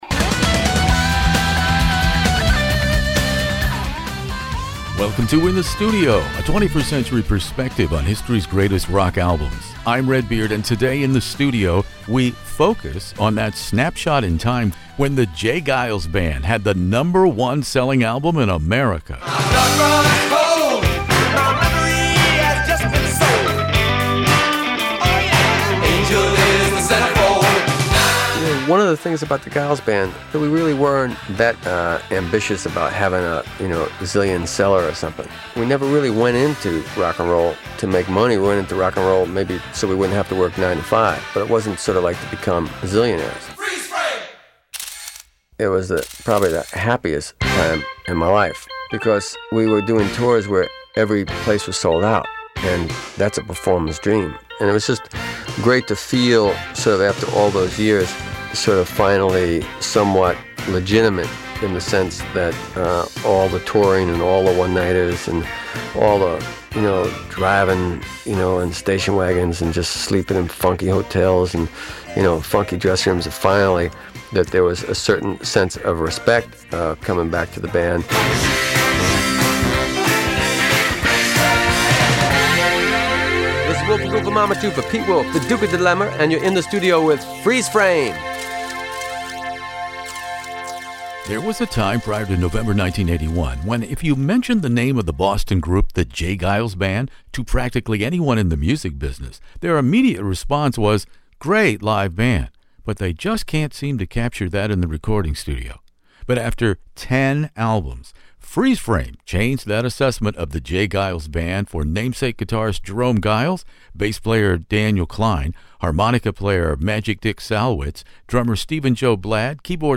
Freeze Frame , with #1 sales driven by hits “Centerfold” and the title track, changed all of that for the J Geils Band. Peter Wolf recalls In The Studio in this classic rock interview.